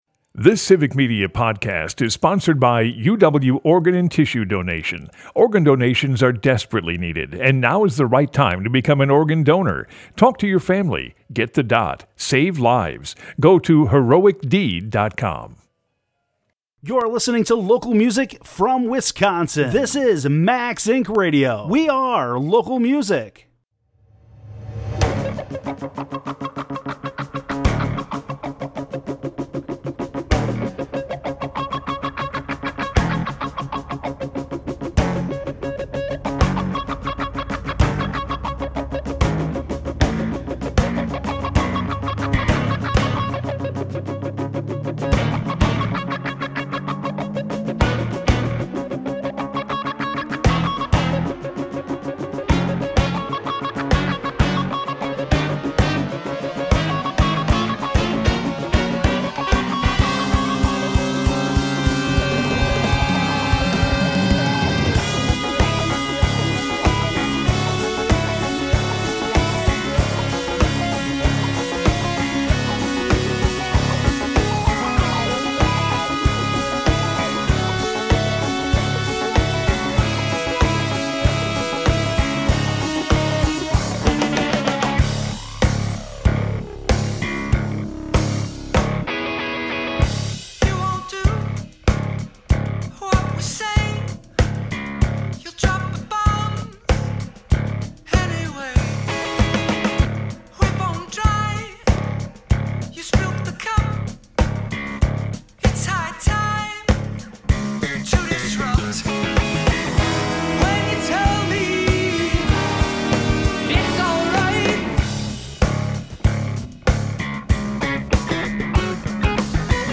Max Ink Radio is Wisconsin’s local music radio show originating in Madison and featuring music, interviews, performances, and premieres of Wisconsin artists.